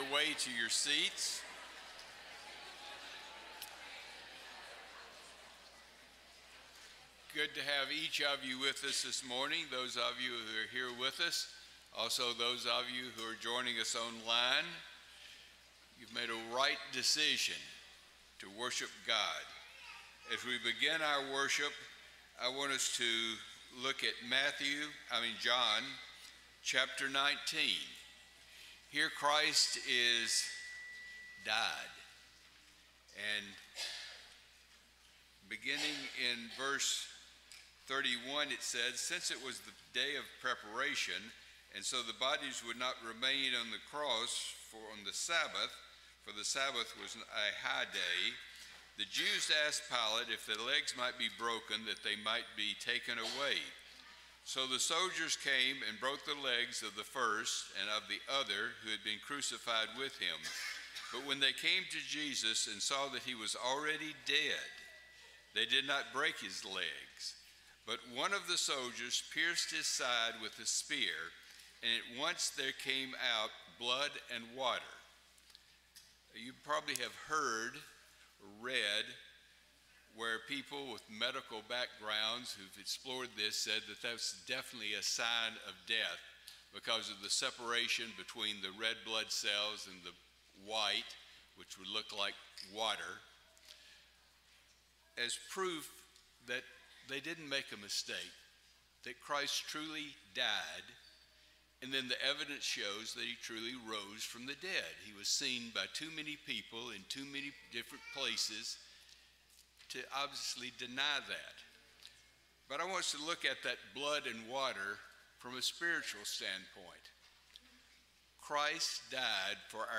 Matthew 7:15, English Standard Version Series: Sunday AM Service